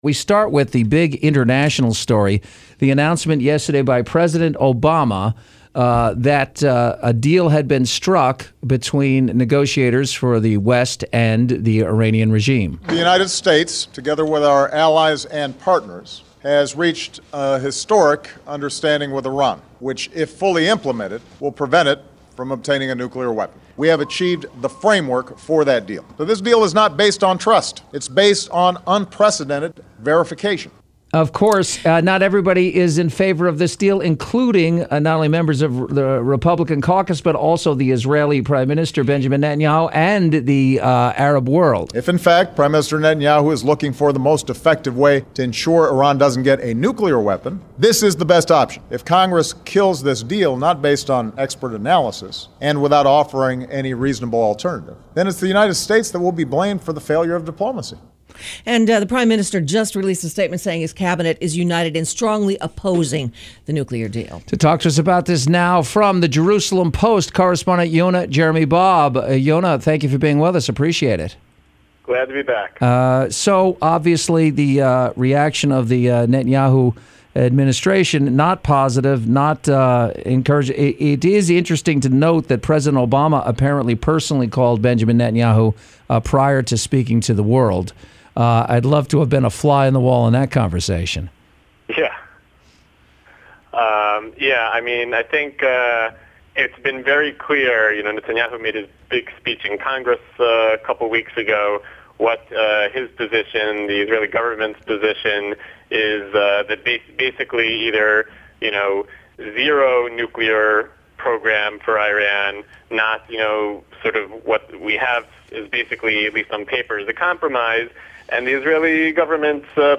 Interview with KABC Los Angeles Radio – US-Israel Relations, Netanyahu Speech, Nuclear Iran 3/5/2015